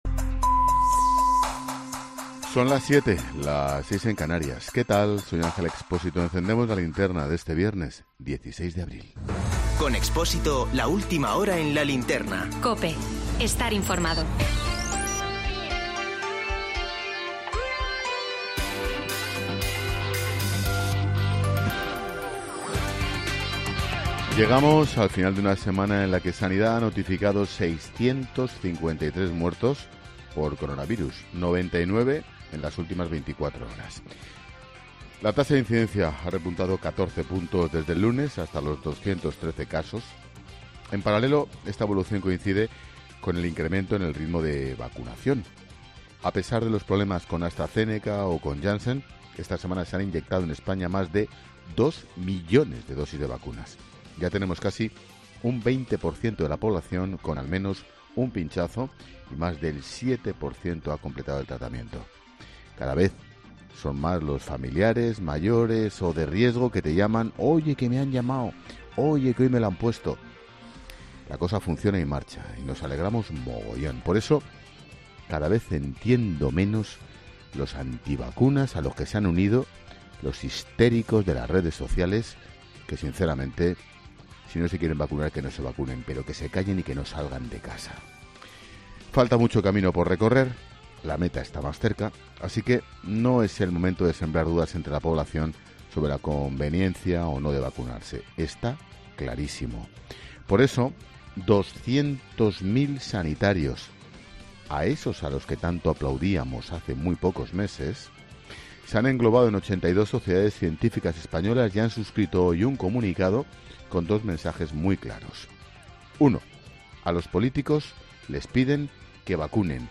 Monólogo de Expósito.